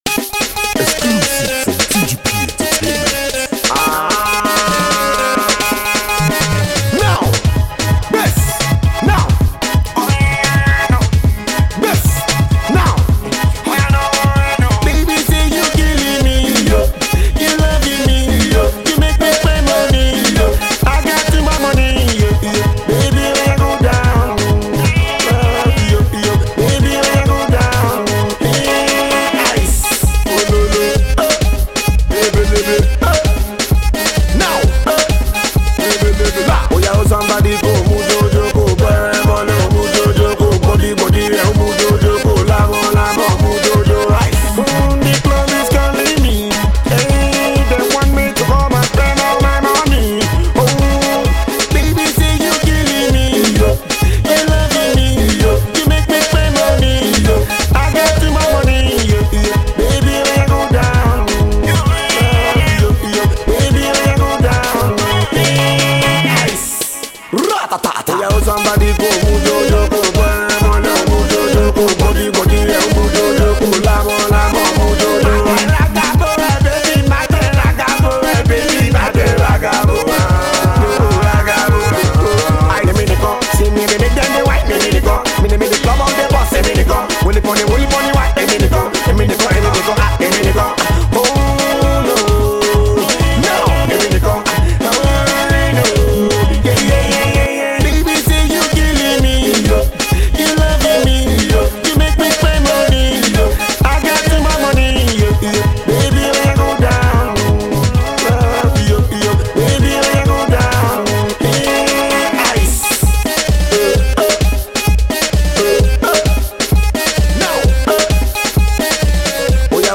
bass heavy Club banger